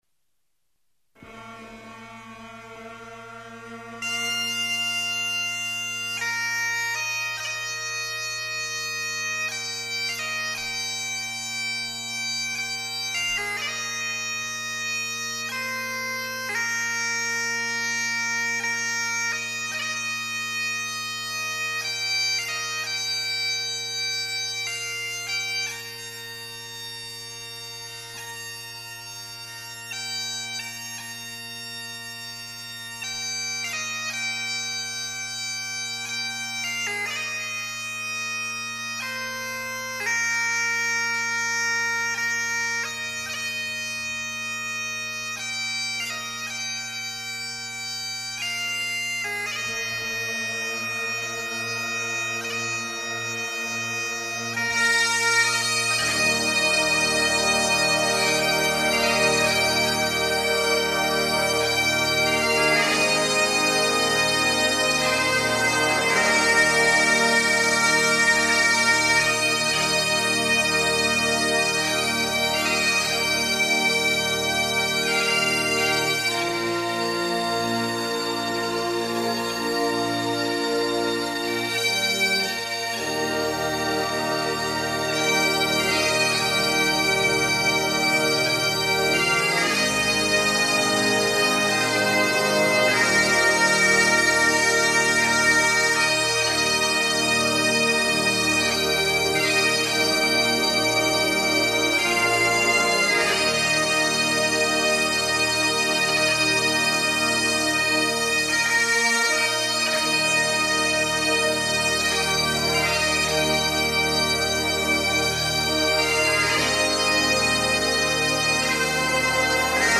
Piper
Amazing Grace ( Scottish Bag Pipes )
Braveheart- Irish-CELTIC BAGPIPES -  Amazing Grace Bagpipes.mp3